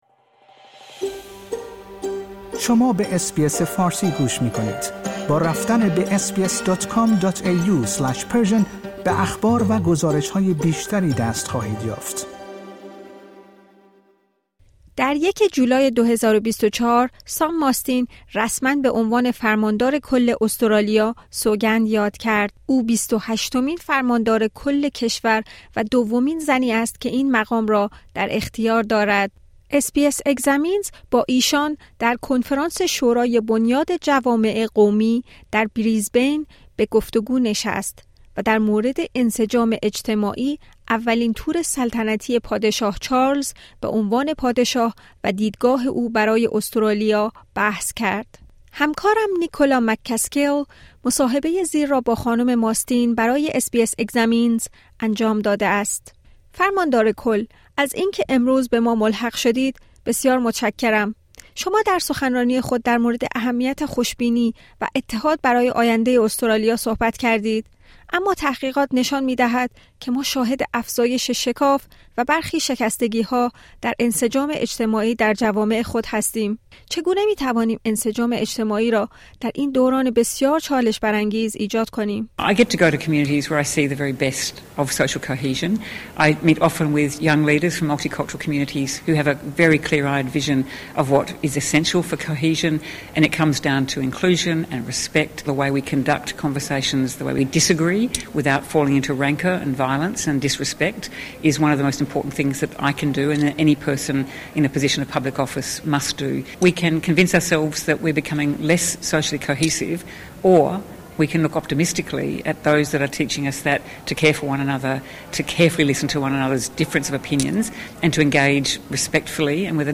در گفتگو با فرماندار کل :SBS Examines